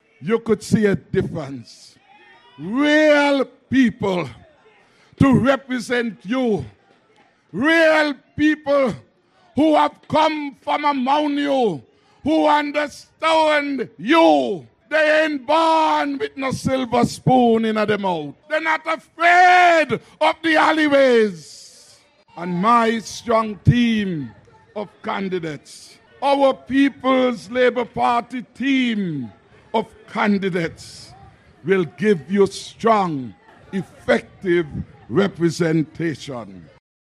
The People’s Labour Party (PLP) launched its full slate of Candidates on (Saturday night) June 18th, at Greenlands Pasture, St. Kitts as the Federation prepares for the imminent General Elections.
Prime Minister and Leader of the People’s Labour Party (PLP), Dr. Timothy Harris, made this comment about his PLP Candidates.
Prime Minister, Dr. Timothy Harris.